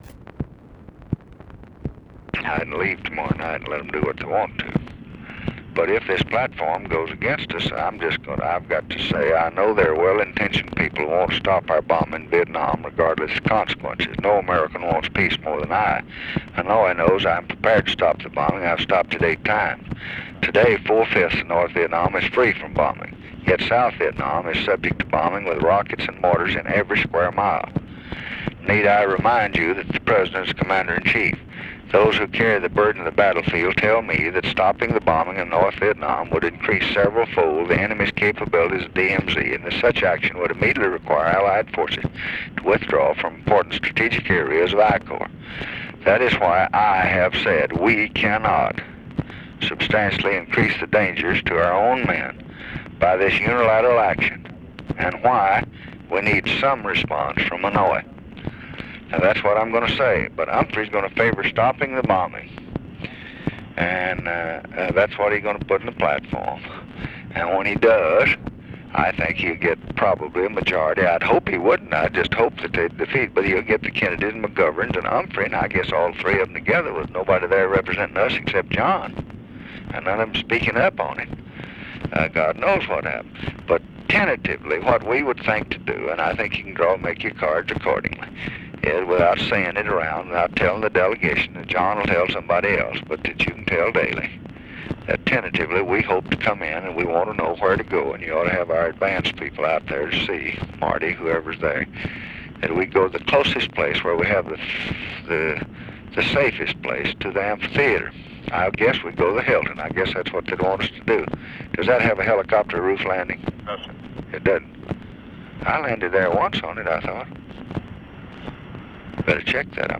Conversation with MARVIN WATSON, August 26, 1968